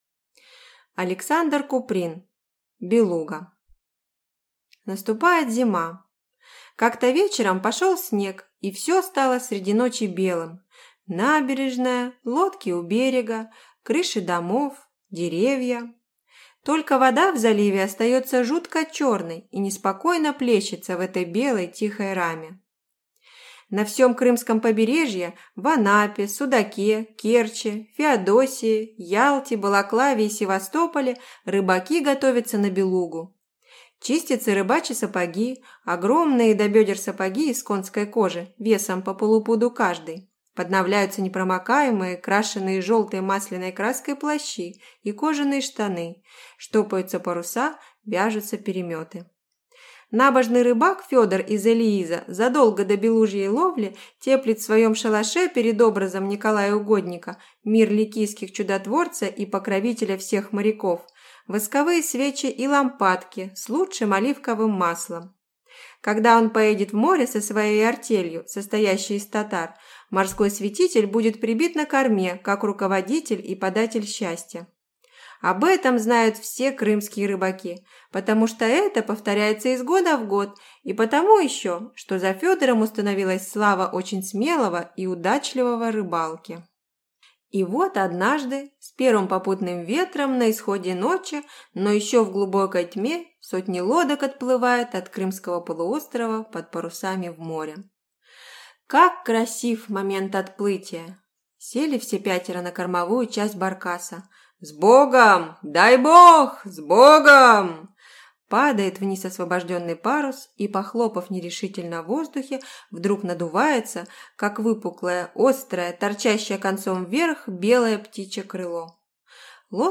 Аудиокнига Белуга | Библиотека аудиокниг